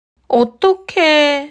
強調して「オットッケー」のように語尾を伸ばすことも多いです。